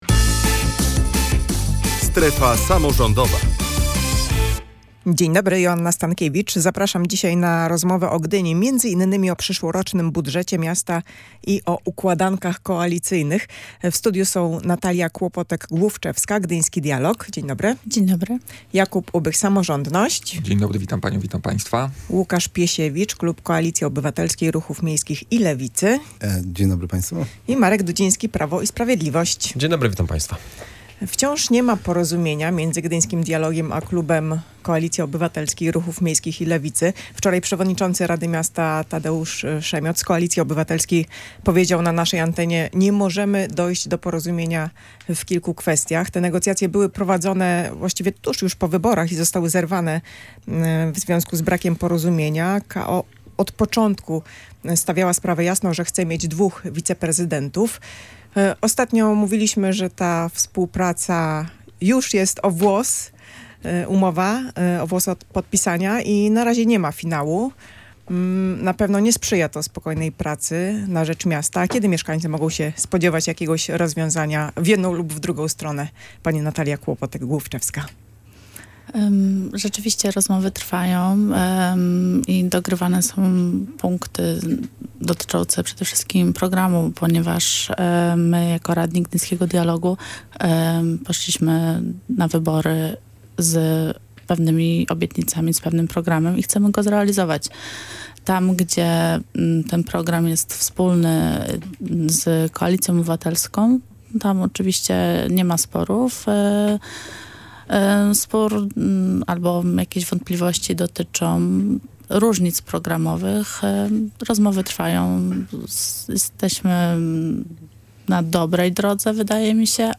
Między innymi na te tematy wypowiadali się goście audycji „Strefa Samorządowa”: Natalia Kłopotek-Główczewska (Gdyński Dialog), Jakub Ubych (Samorządność), Łukasz Piesiewicz (klub Koalicji Obywatelskiej, Ruchów Miejskich i Lewicy), Marek Dudziński (Prawo i Sprawiedliwość).